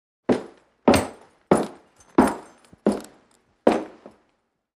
FootstepsBootsWood PE770103
FOLEY FOOTSTEPS BOOTS: INT: Western boots & spurs on wood, quick walk & stop, echo.